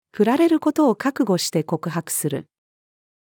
振られる事を覚悟して告白する。-female.mp3